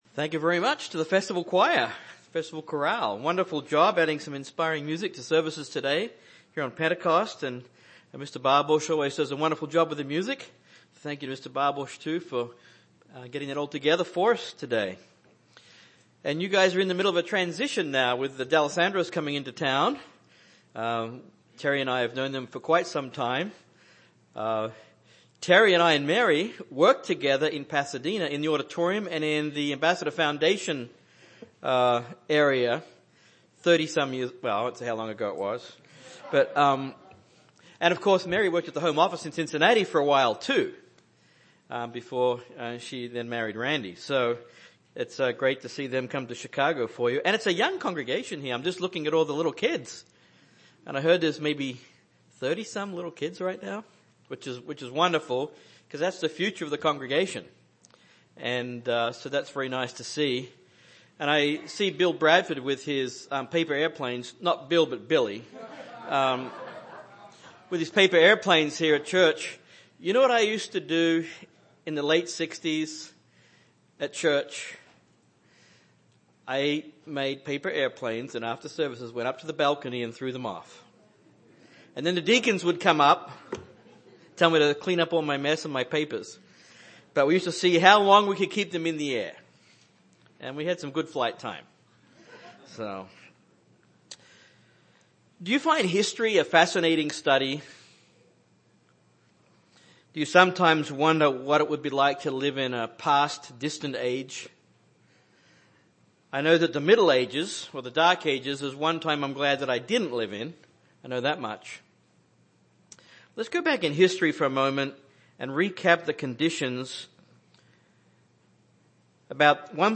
This sermon was given on the Feast of Pentecost.